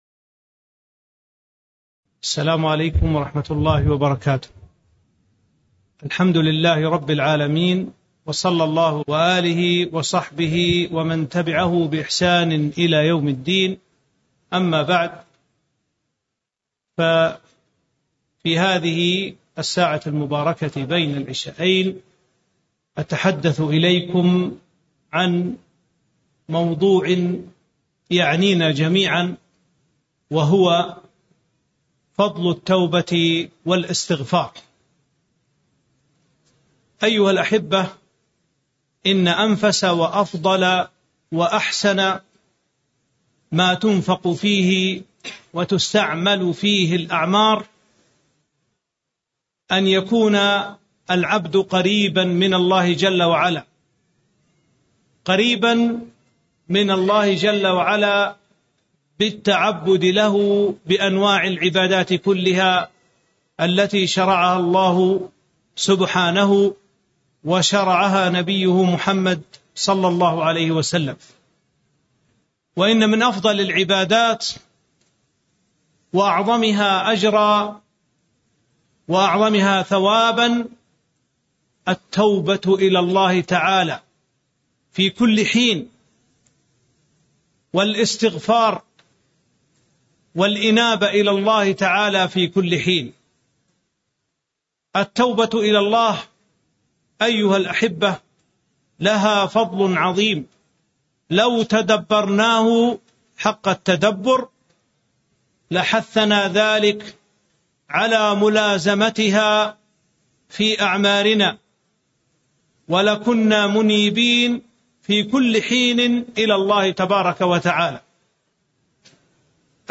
تاريخ النشر ٢٢ ذو الحجة ١٤٤٣ هـ المكان: المسجد النبوي الشيخ